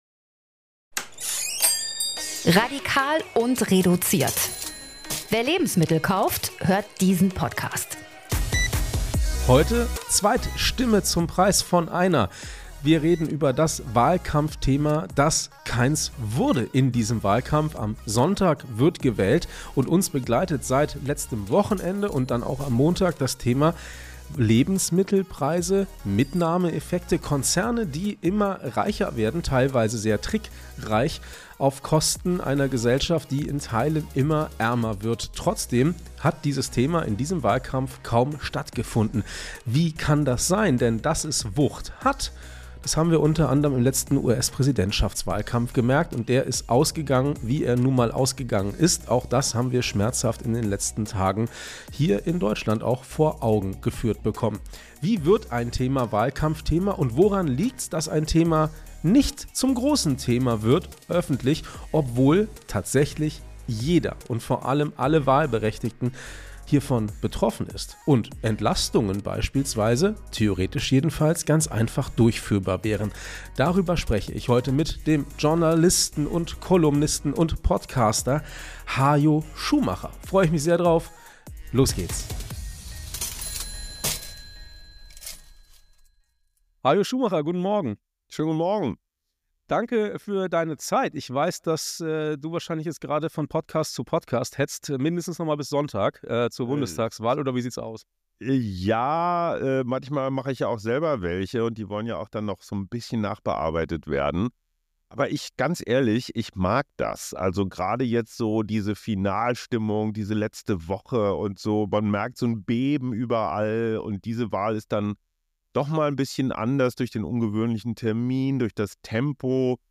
Darüber und die Frage, ob Abgeordnete gefühlskalt sind, rede ich mit Hajo Schumacher, Journalist und Podcaster und Kolumnist und und und!